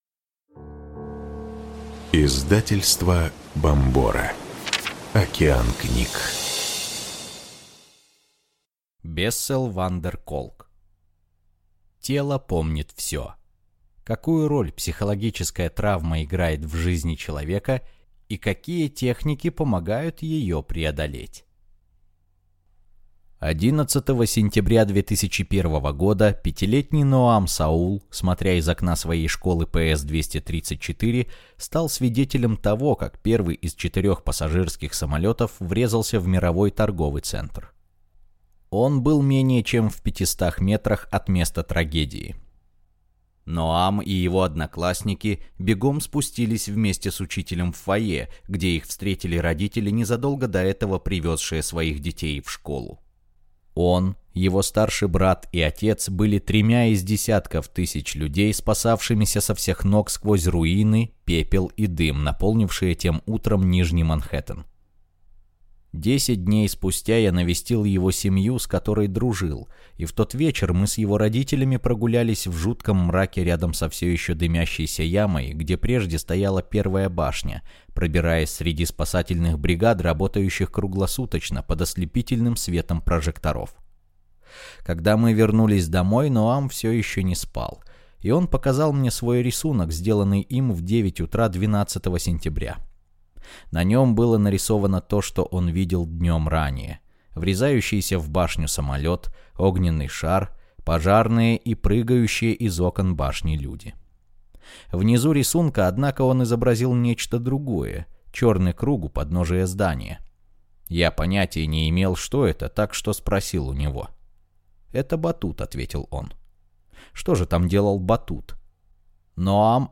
Аудиокнига Тело помнит все. Какую роль психологическая травма играет в жизни человека и какие техники помогают ее преодолеть | Библиотека аудиокниг